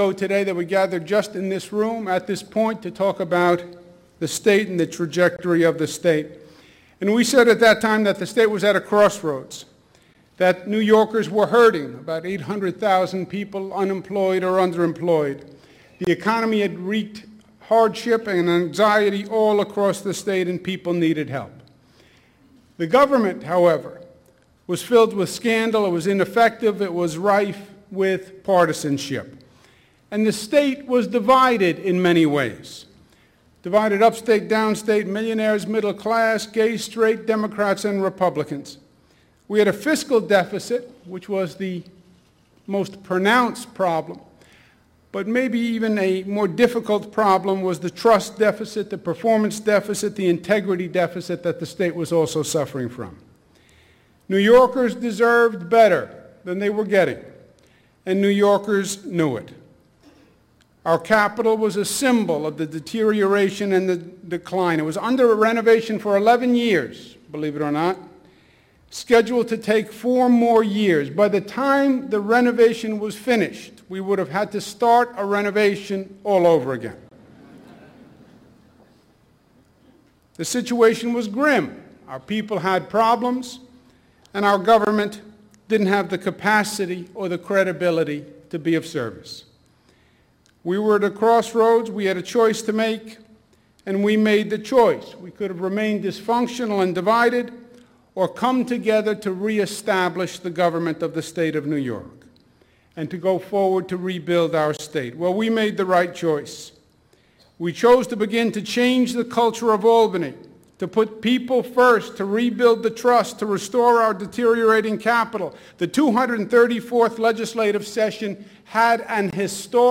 First few moments of Gov. Andrew Cuomo's State of the State Address.